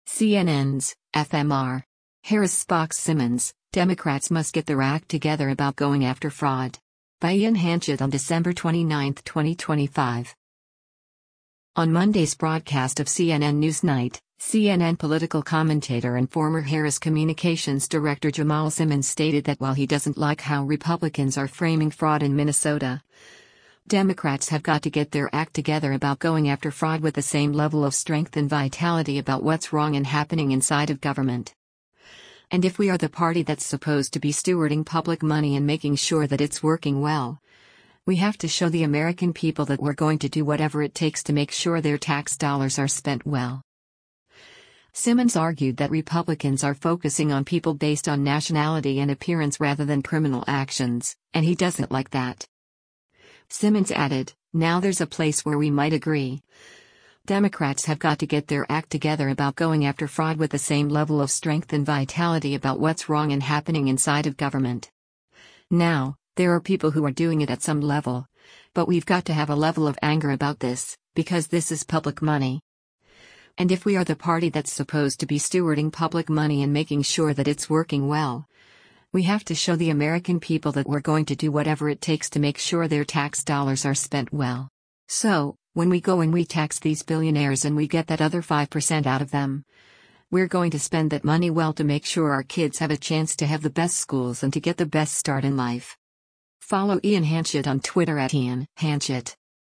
On Monday’s broadcast of “CNN NewsNight,” CNN Political Commentator and former Harris Communications Director Jamal Simmons stated that while he doesn’t like how Republicans are framing fraud in Minnesota, “Democrats have got to get their act together about going after fraud with the same level of strength and vitality about what’s wrong and happening inside of government.”